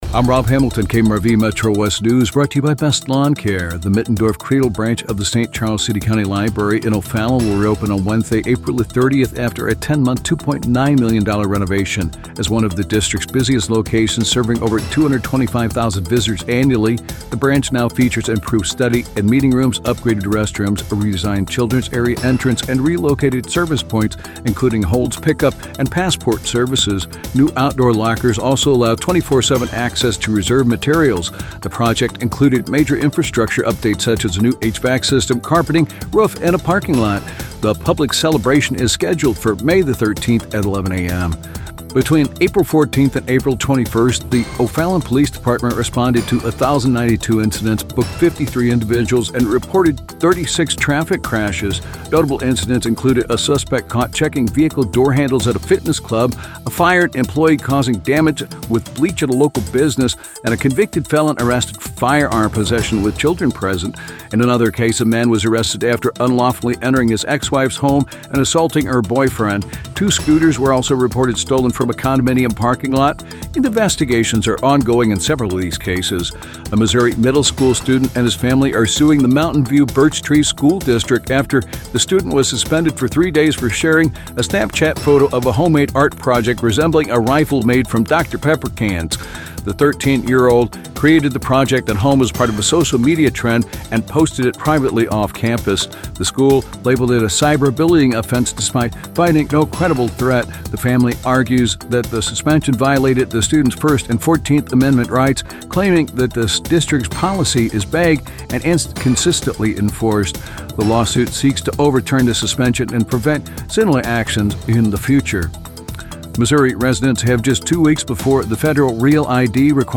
by | Apr 24, 2025 | Local News